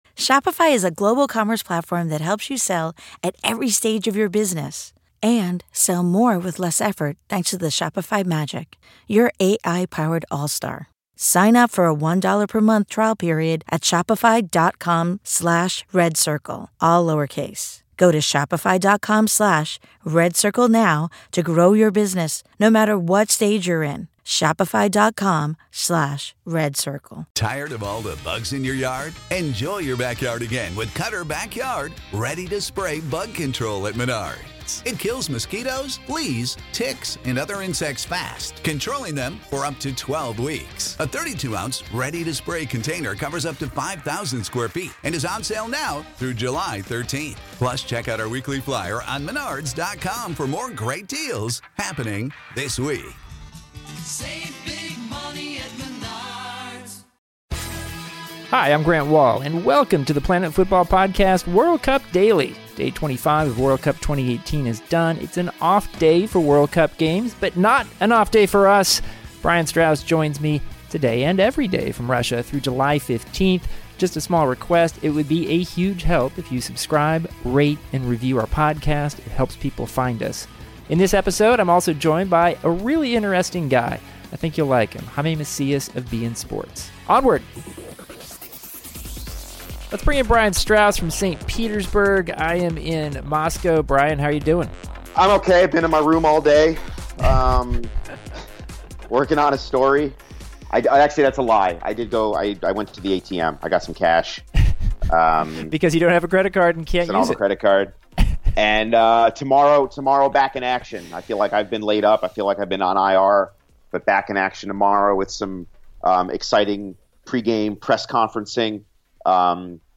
Then Grant is joined for a fun interview